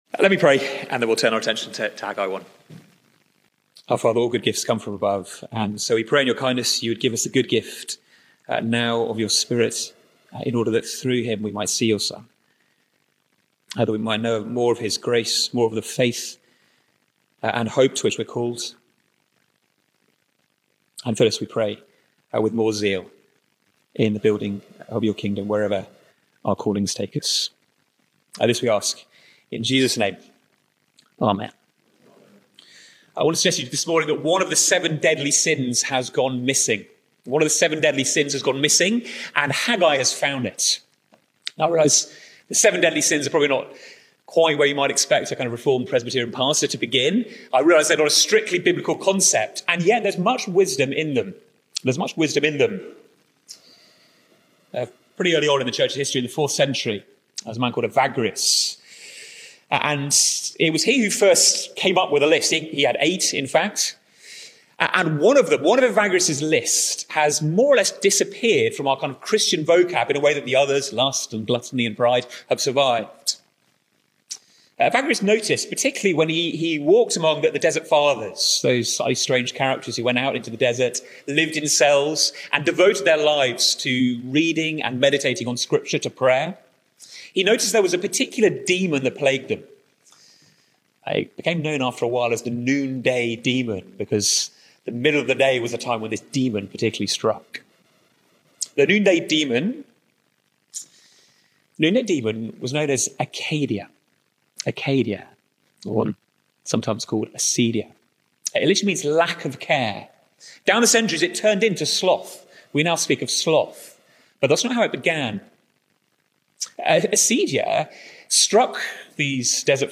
Chapel Talks